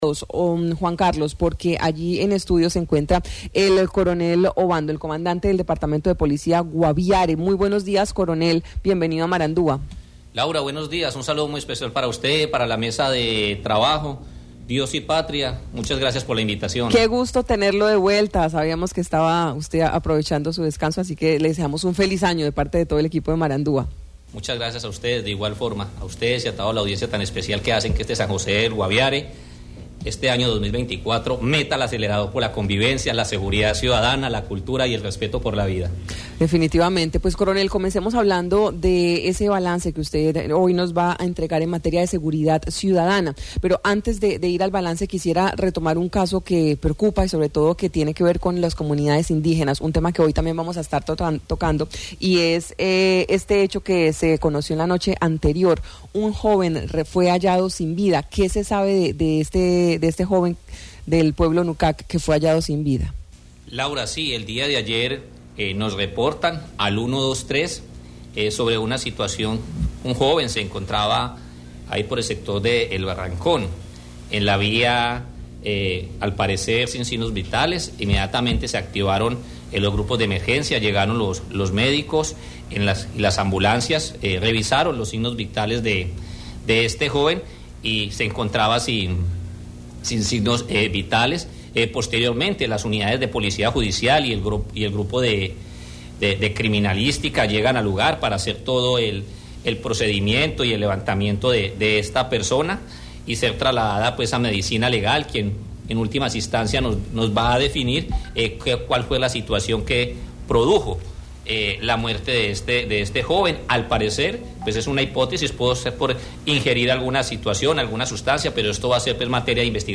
El coronel Alexander Obando, comandante del Departamento de Policía Guaviare, dio detalles del joven Nukak hallado sin vida en la vía nacional